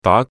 Yue-daa2.mp3